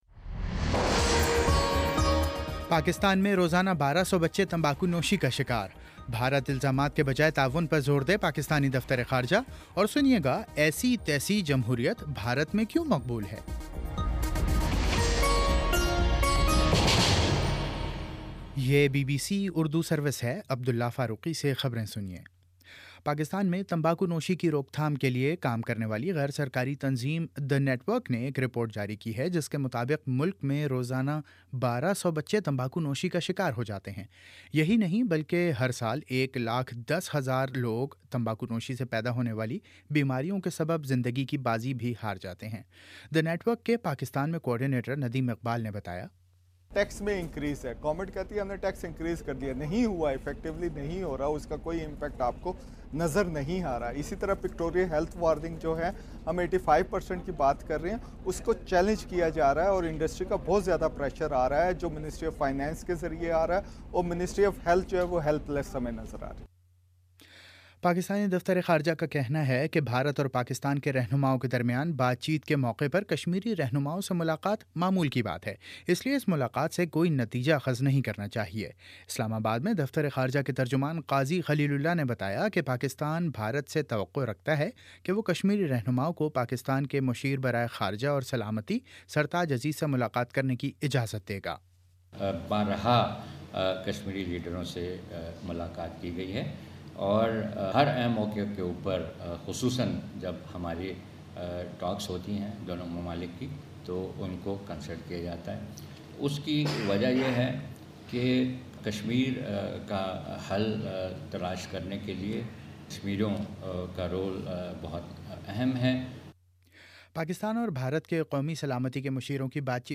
اگست 20: شام پانچ بجے کا نیوز بُلیٹن